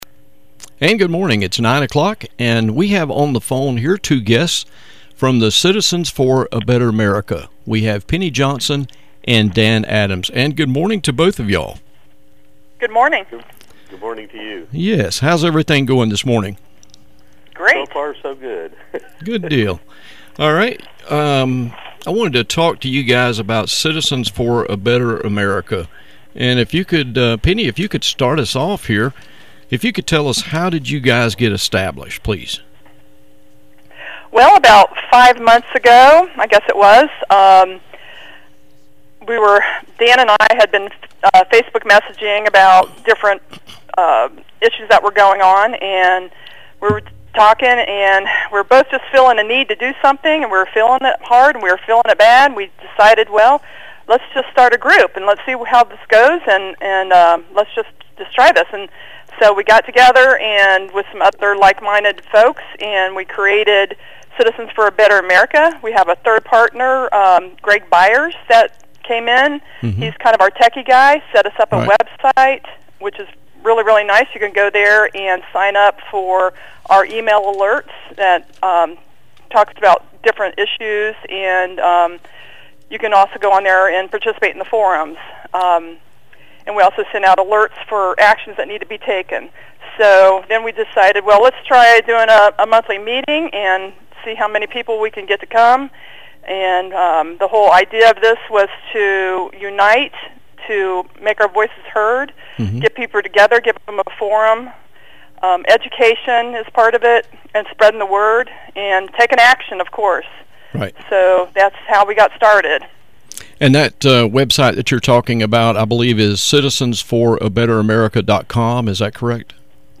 WCNG/WCVP 8/15 Radio Interview - Citizens for a Better America